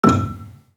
Gambang-E5-f.wav